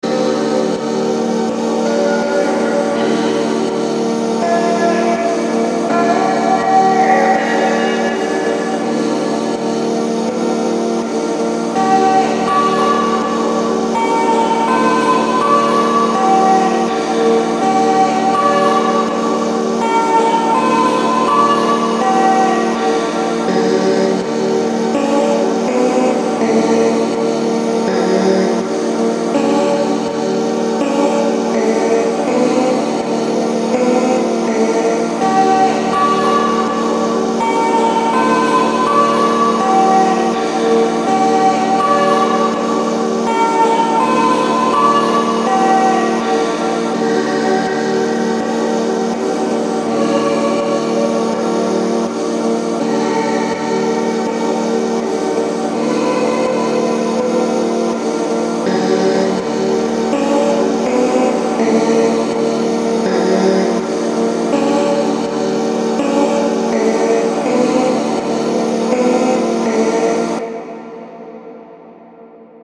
Longer remix of my ethereal loop.